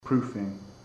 Προφορά
{‘pru:fıŋ}